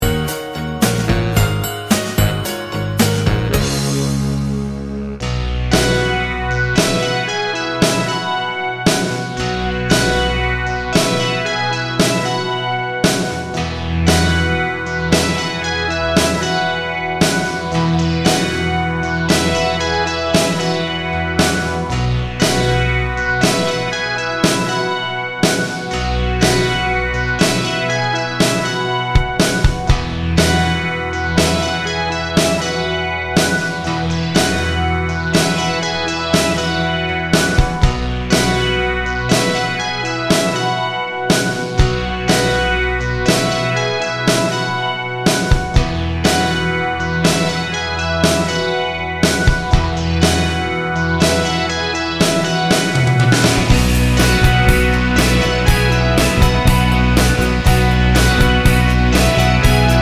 Medleys